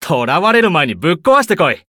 文件 文件历史 文件用途 全域文件用途 Ja_Bhan_fw_04.ogg （Ogg Vorbis声音文件，长度1.9秒，112 kbps，文件大小：26 KB） 源地址:游戏语音 文件历史 点击某个日期/时间查看对应时刻的文件。 日期/时间 缩略图 大小 用户 备注 当前 2018年5月25日 (五) 03:00 1.9秒 （26 KB） 地下城与勇士  （ 留言 | 贡献 ） 分类:巴恩·巴休特 分类:地下城与勇士 源地址:游戏语音 您不可以覆盖此文件。